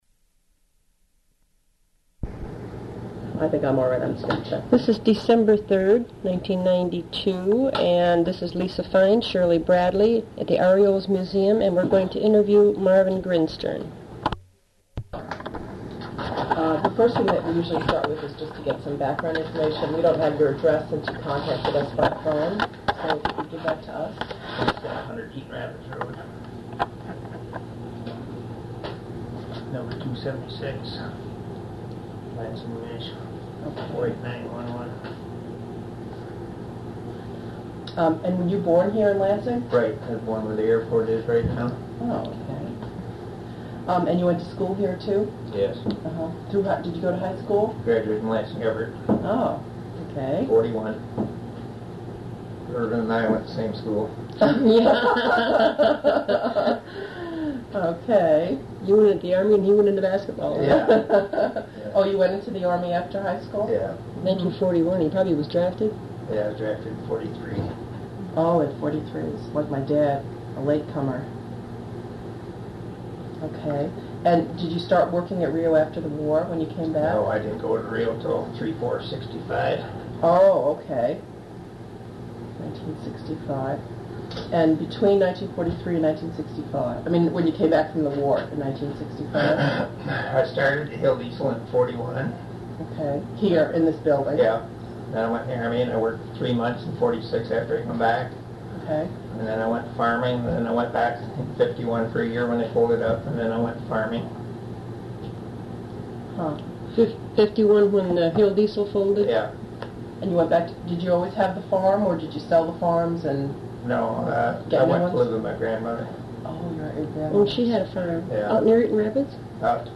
Recorded as part of the REO Memories oral history project.
Interviewed and recorded in Lansing, MI